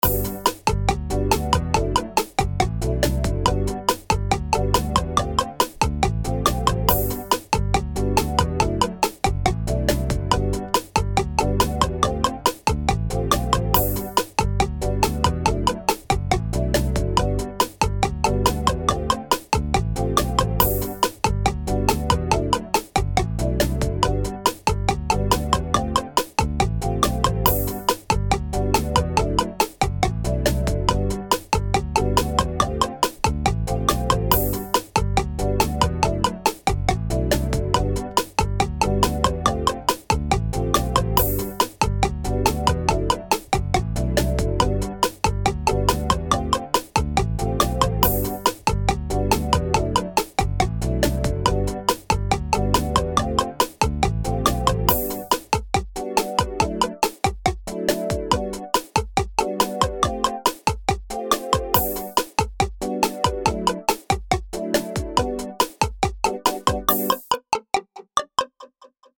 かわいい
メロディラインがポコポコしている、シンプルで可愛らしいBGMです。